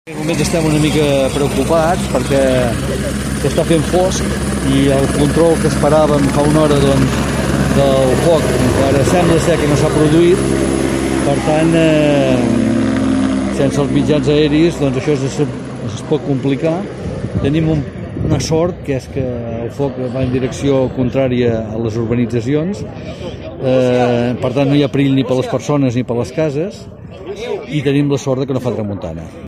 Així ho ha explicat a Ràdio Capital, Jordi Colomí, alcalde de Torroella de Montgrí i l’Estartit.